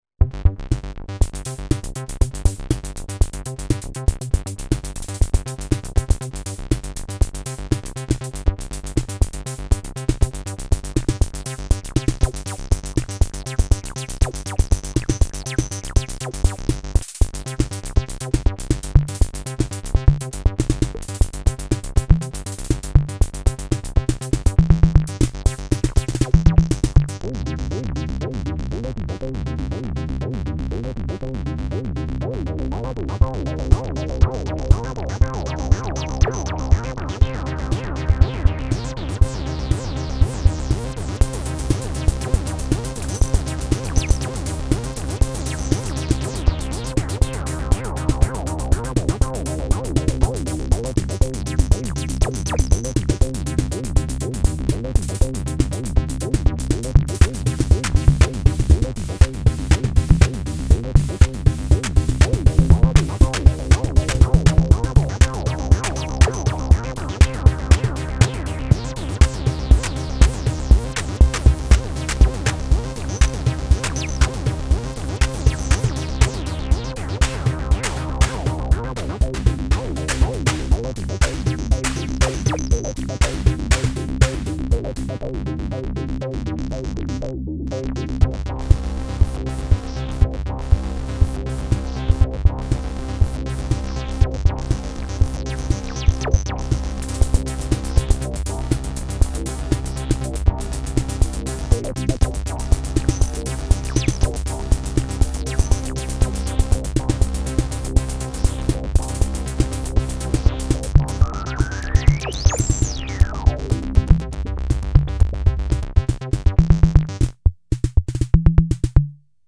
Portable analog drum machine with sequencer, Midi and some real-time knobs.
editDRUM The 502 voice board generates 8 analog percussions (some are channel-shared) each with individual mix gain:
a jam with synthlite II / shruthi